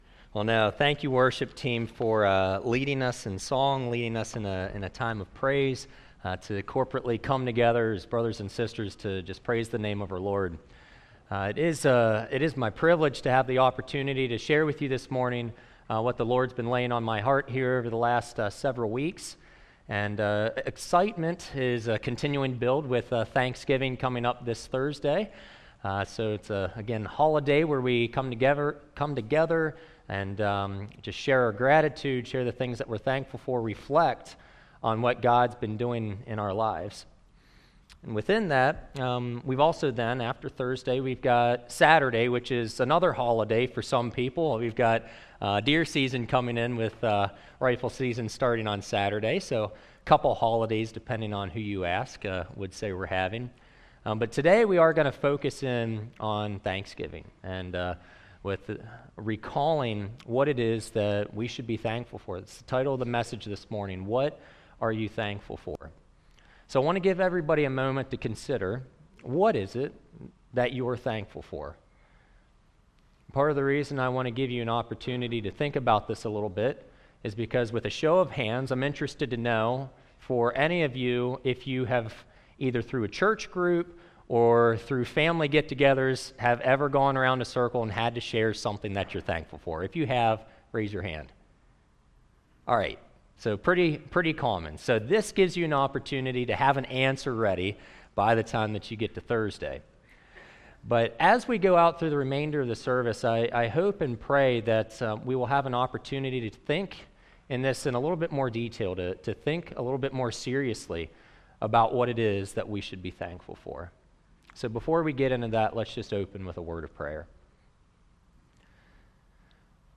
Thanksgiving Message What are you thankful for?1 Thessalonians 5:16-18 Always be joyful. 17 Never stop praying. 18 Be thankful in all circumstances, for this is God’s will for you who belong to Christ Jesus.